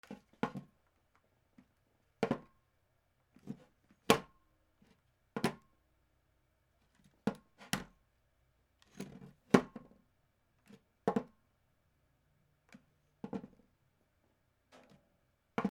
/ J｜フォーリー(布ずれ・動作) / J-14 ｜置く